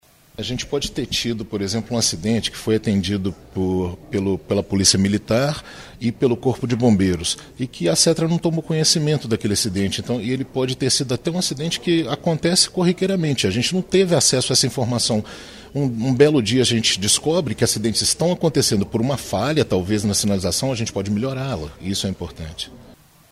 O secretário de Transporte e Trânsito de Juiz de Fora, Eduardo Facio detalha um exemplo de como o projeto funciona na prática.